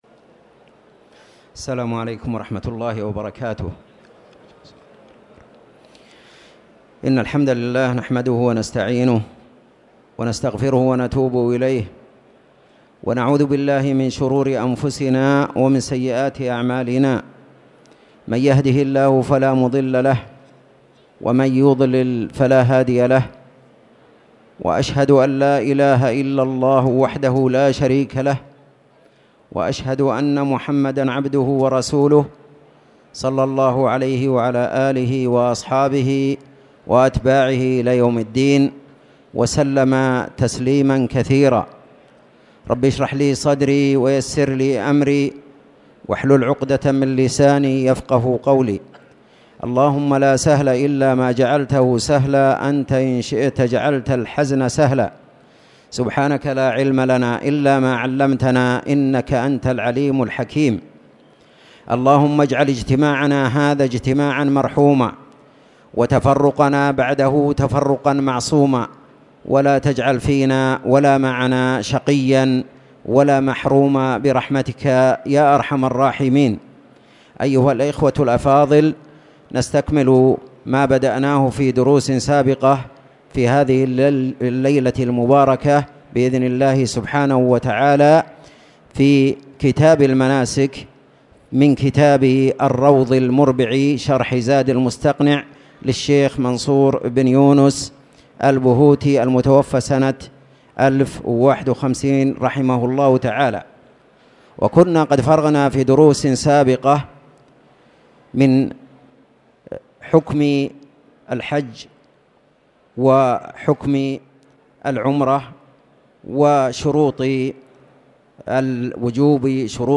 تاريخ النشر ٦ رجب ١٤٣٨ هـ المكان: المسجد الحرام الشيخ